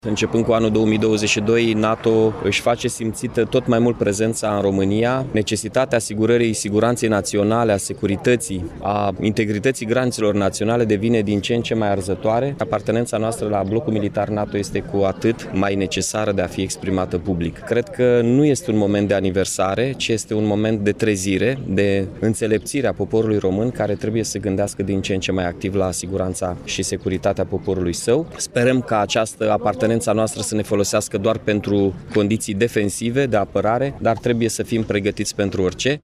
Ziua NATO a fost marcată astăzi la Iași printr-un ceremonial militar desfășurat în Piața Palatului.
Au fost rostite mai multe discursuri cu prilejul împlinirii a 75 de ani de la înființarea Alianței Nord-Atlantice, precum și pe fondul aniversării a 20 de ani de la aderarea României la acest organism.
La rândul său, primarul Iașiului, Mihai Chirica, a subliniat că dezbaterile privind repunerea în funcție a unor elemente de infrastructură militară și civilă, reorganizarea armatei și dotarea acesteia sunt necesare în contextul actual: